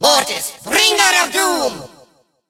evil_mortis_start_vo_02.ogg